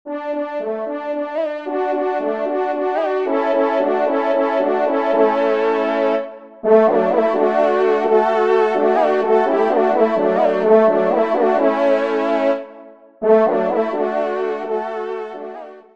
Genre : Musique Religieuse pour Quatre Trompes ou Cors
Pupitre 3° Trompe